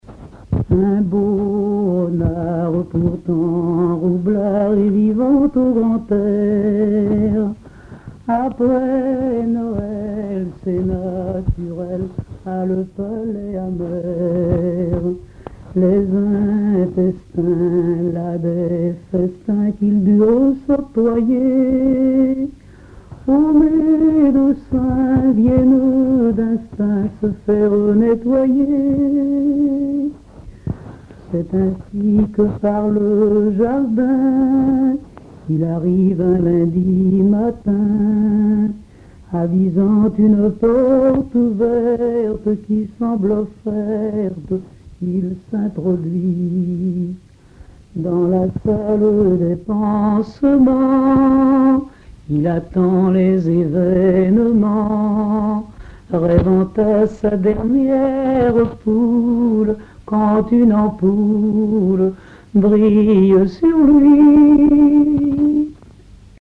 Récits et chansons en patois
Pièce musicale inédite